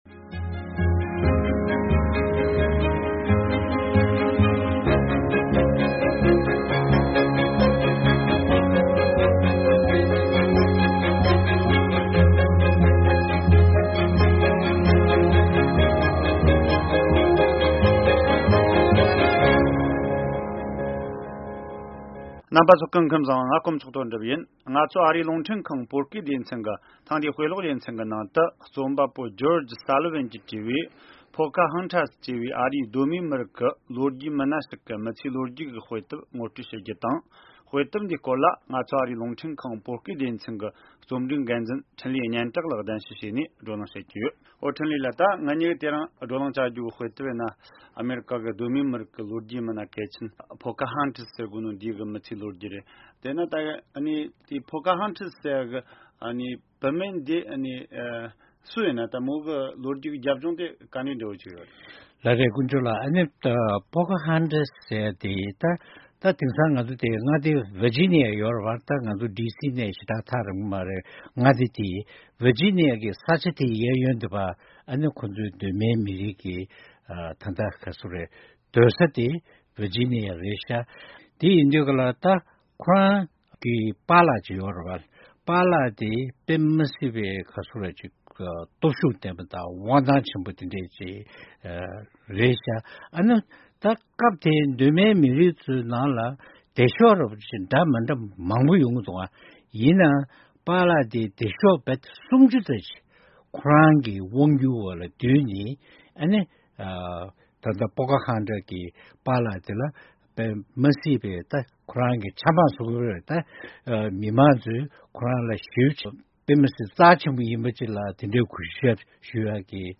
བགྲོ་གླེང་ཞུས་ཡོད།